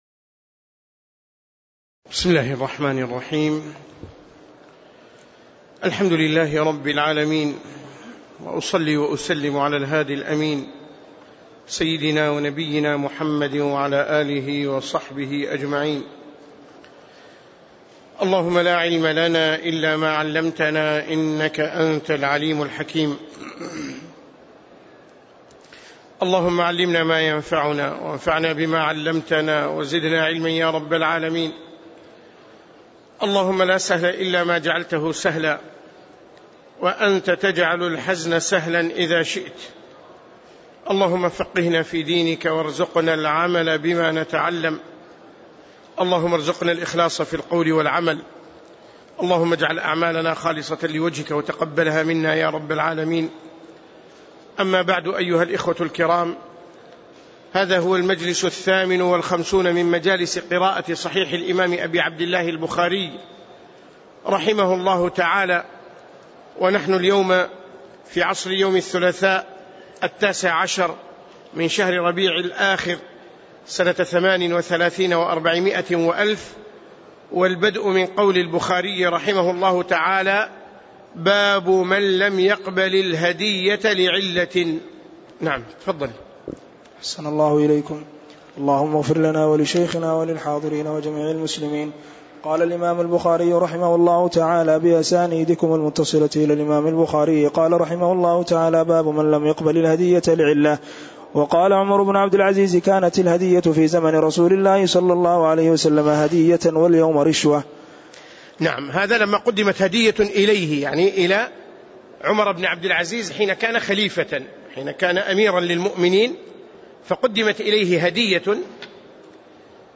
تاريخ النشر ١٩ ربيع الثاني ١٤٣٨ هـ المكان: المسجد النبوي الشيخ